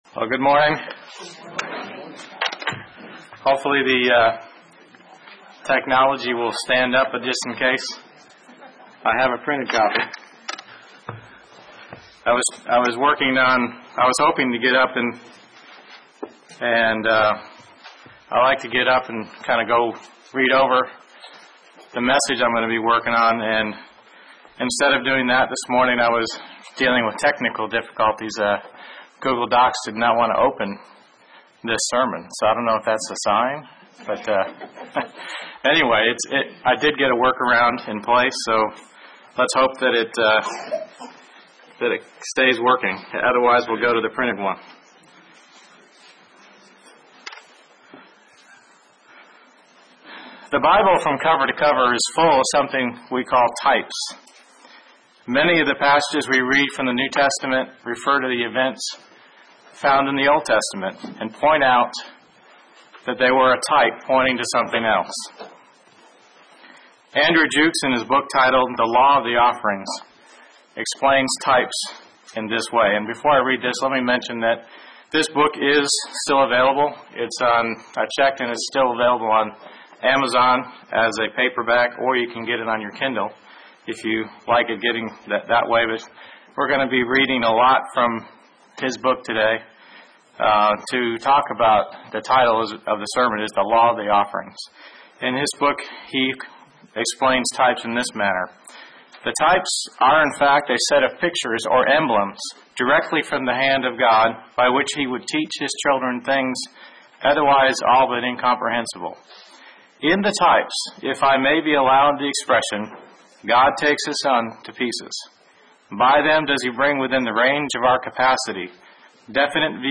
Given in Murfreesboro, TN
UCG Sermon Studying the bible?